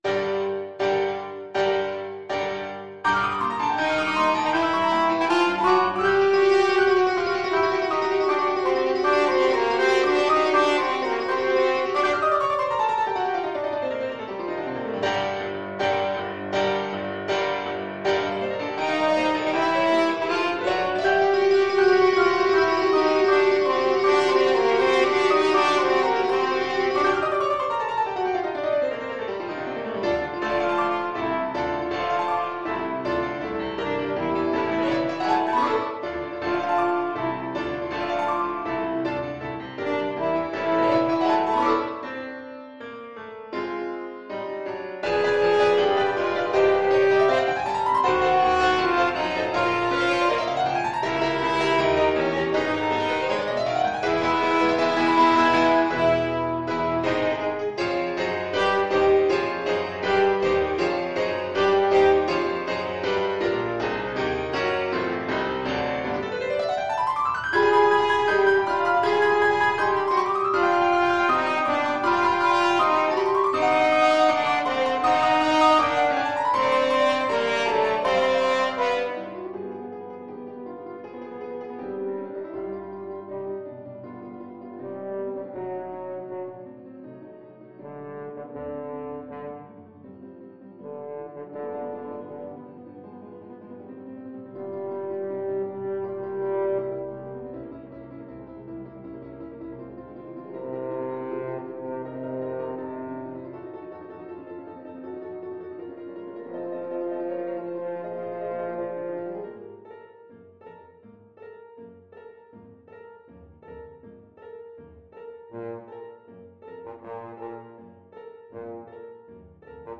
French Horn
Allegro agitato (=80) (View more music marked Allegro)
G minor (Sounding Pitch) D minor (French Horn in F) (View more G minor Music for French Horn )
Classical (View more Classical French Horn Music)